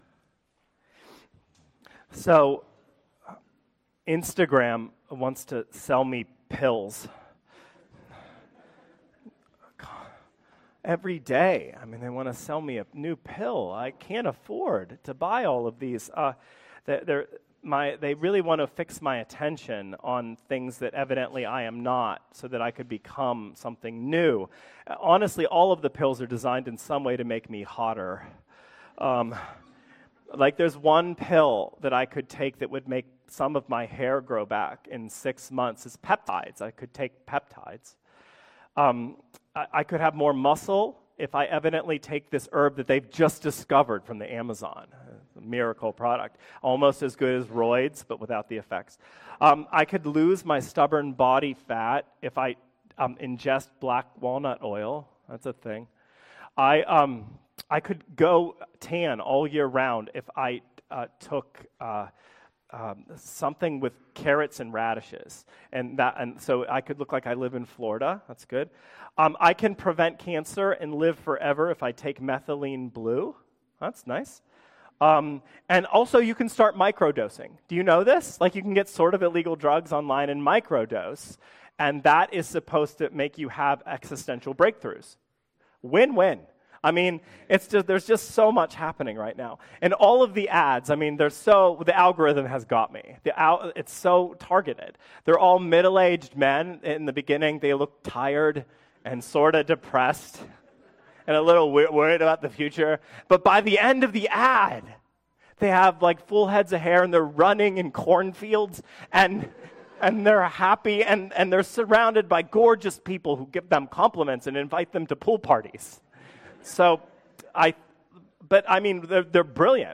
2026 Sermons